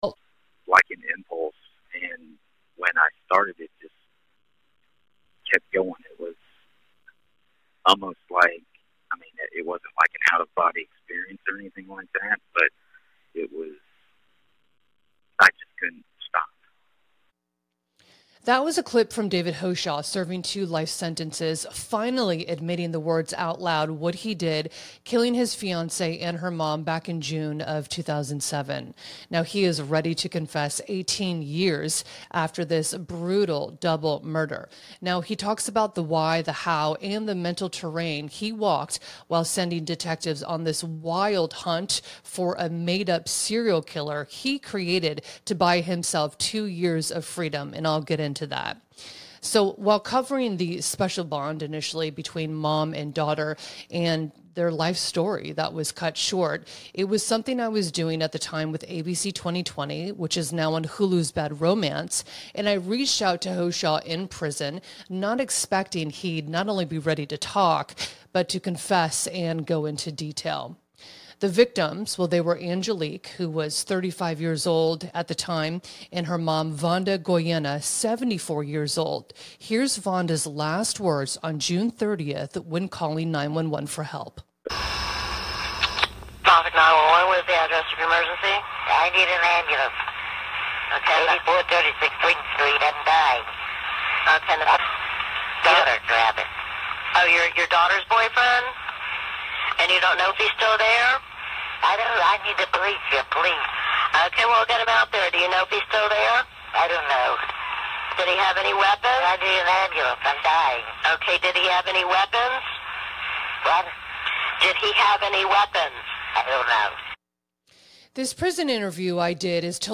An exclusive interview with a convicted killer, which I've done many in the past throughout my career, but this one lead to an unexpected confession and details of the "Why" and "How" he did the act, plus how he intentionally sent detectives on a wild hunt for a made-up serial killer to buy himself two years of freedom.